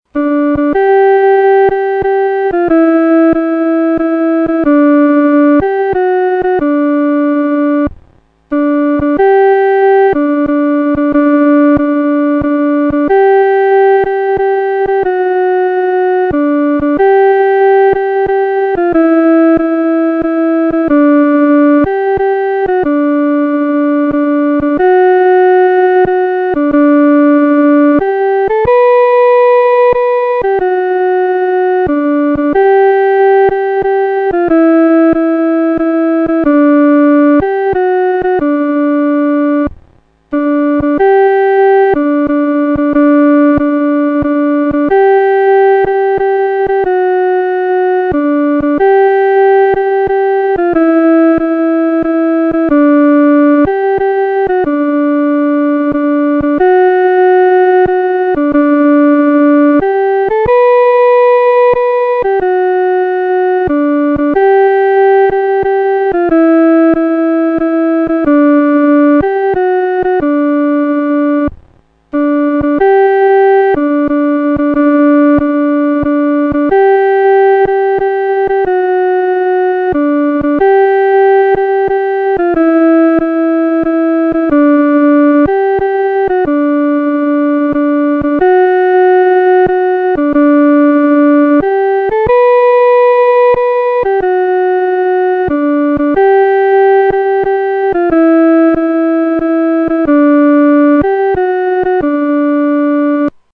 独奏（第二声）
靠近主-独奏（第二声）.mp3